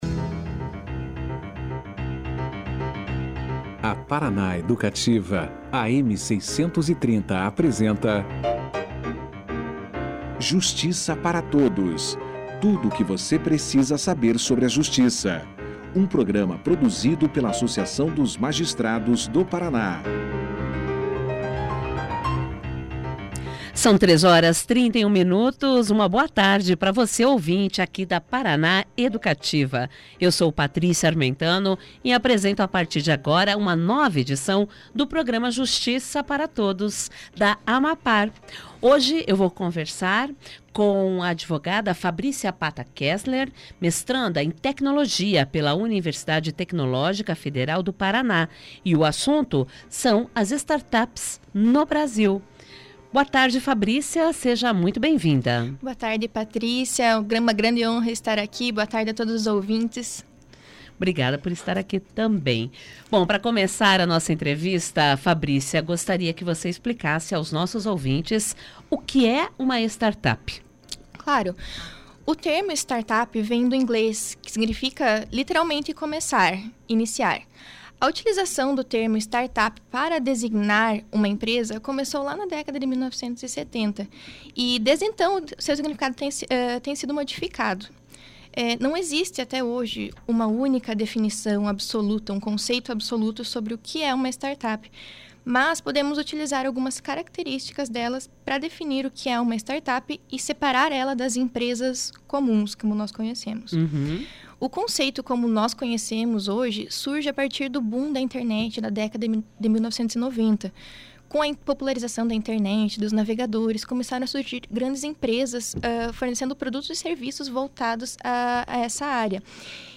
Na oportunidade, ela também falou a respeito do cenário americano e europeu referente a este novo modelo de negócio e apontou os tipos de startups existentes atualmente. Confira aqui a entrevista na íntegra.